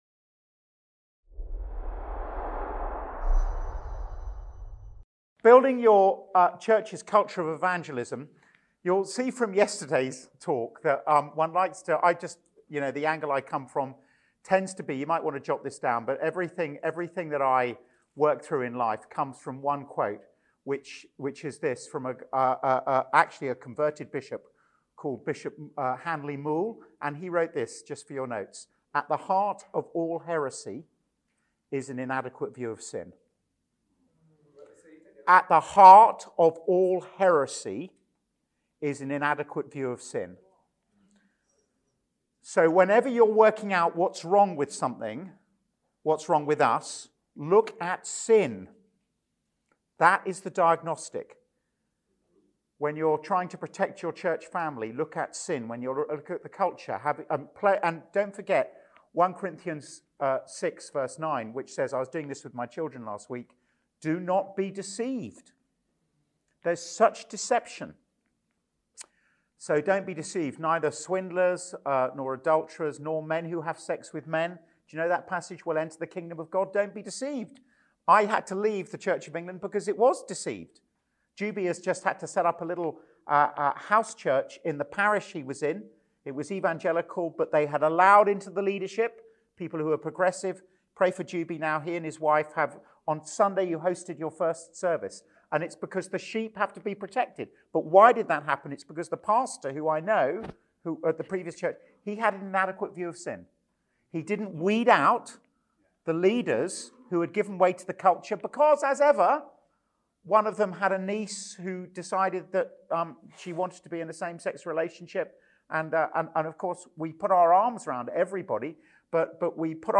2 Corinthians 4:1-6 is perhaps the most important passage on evangelism in the Bible. In this talk, we are thinking through how its key principles of Sovereignty, Integrity and Creativity should underpin everything we seek to do as personal evangelists and as those who seek to build a culture of evangelism in the local church.